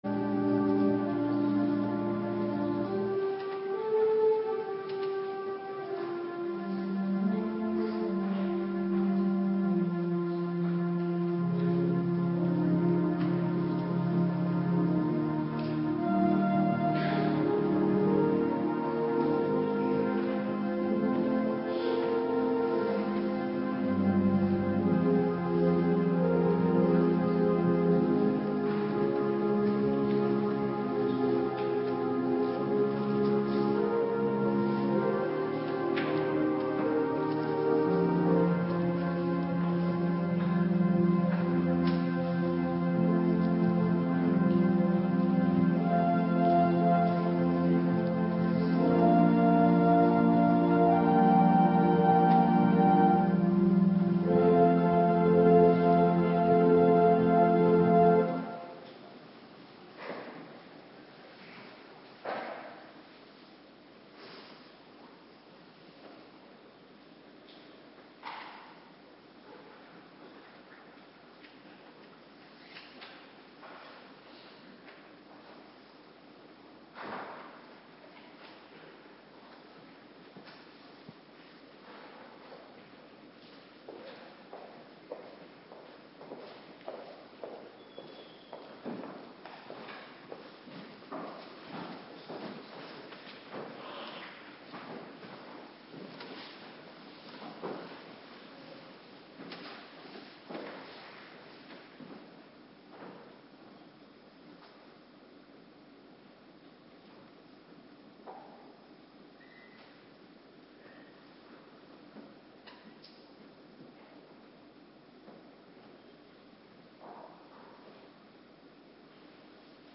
Morgendienst Tweede Kerstdag - Cluster 1
Locatie: Hervormde Gemeente Waarder